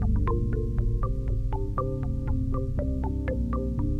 marimbab2.wav